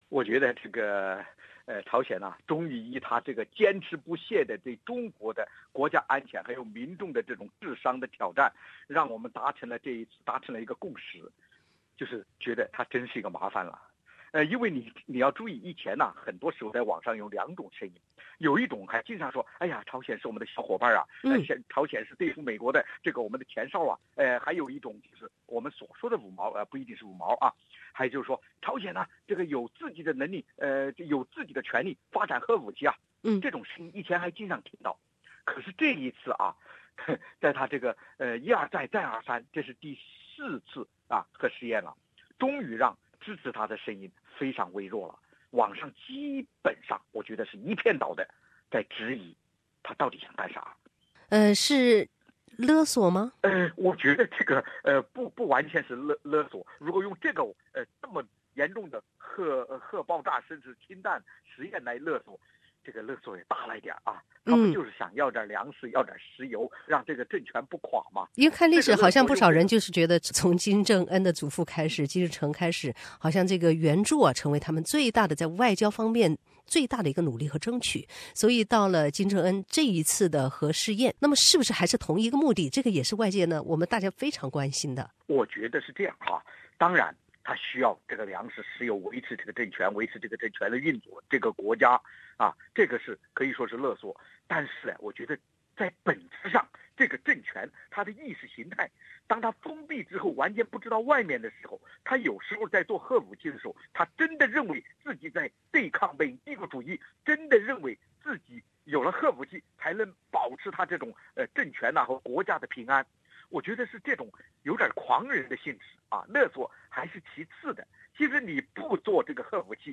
澳洲华人学者、政治评论家杨恒均就朝鲜氢弹试验问题接受SBS电台中文节目采访。 杨恒均表示，朝鲜拥有氢弹，中国受到的威胁非常大，亚太地区的不稳定因素急速增高。